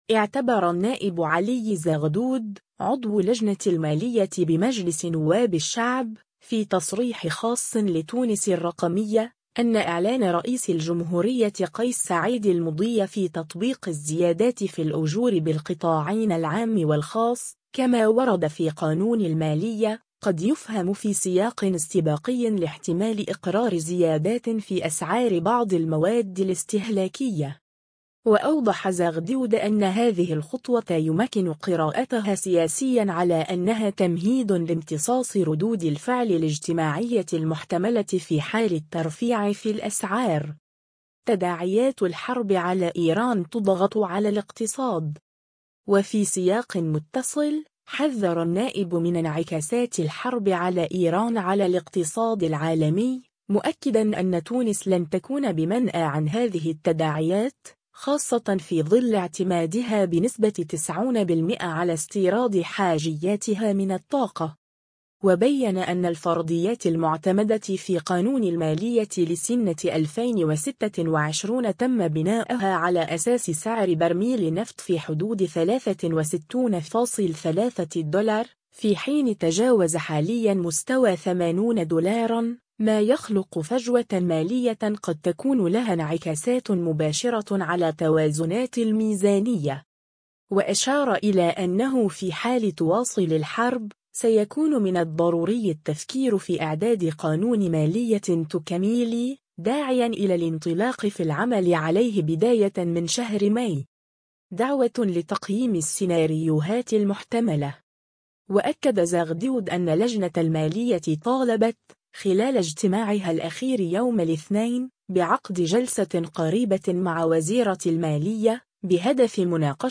اعتبر النائب علي زغدود، عضو لجنة المالية بمجلس نواب الشعب، في تصريح خاص لـ”تونس الرقمية”، أن إعلان رئيس الجمهورية قيس سعيّد المضي في تطبيق الزيادات في الأجور بالقطاعين العام والخاص، كما ورد في قانون المالية، قد يُفهم في سياق استباقي لاحتمال إقرار زيادات في أسعار بعض المواد الاستهلاكية.